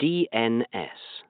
A telephone interactive voice response (IVR) system saying "DNS" in British English